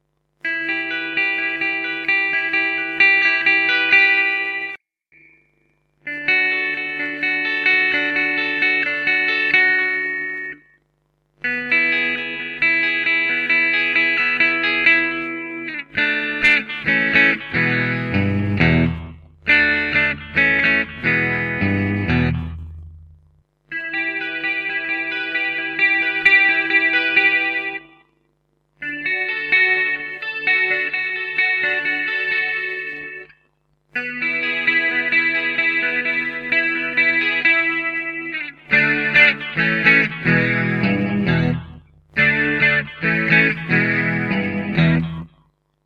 Tsunami 6 alnico 5 single coil for Rickanbacker, classic tone
Based on the 4 and 5 string Tsunamis but wound slightly brighter to ensure more than adequate jangle.
Bridge        Bridge & Neck    Neck